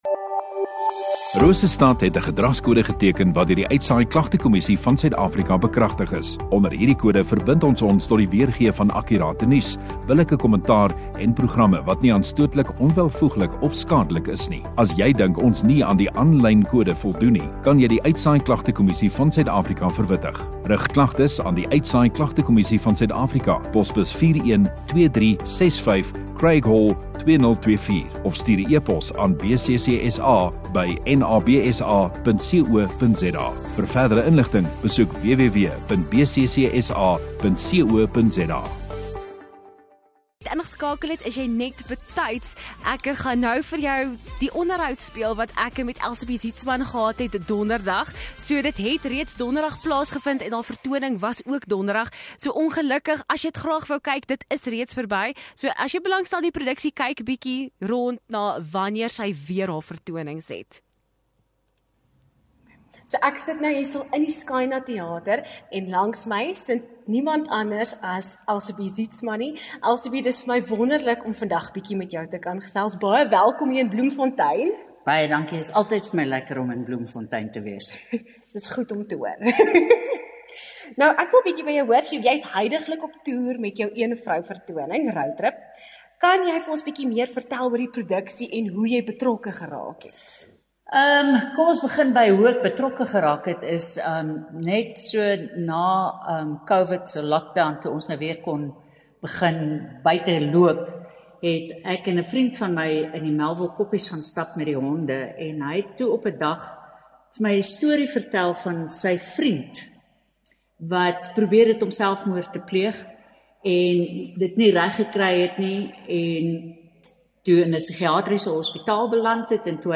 Onderhoude